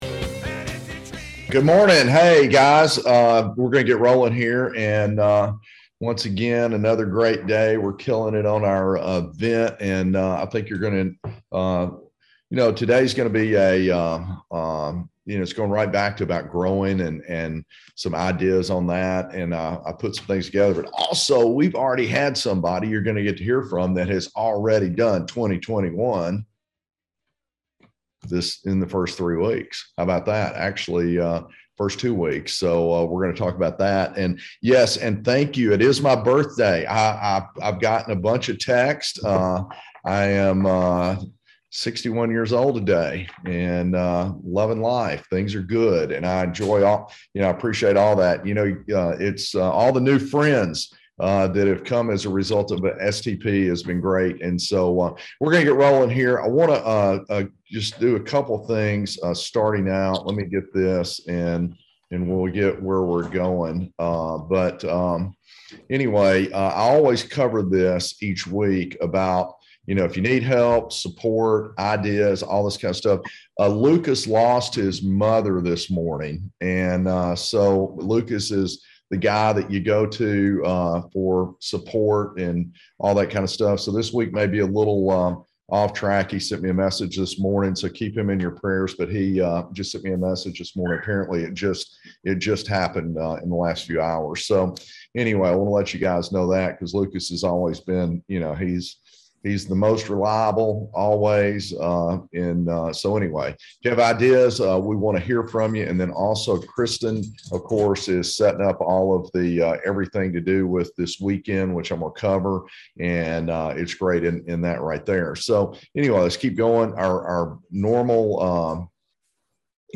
Marketing and Business Webinar - January 18 2022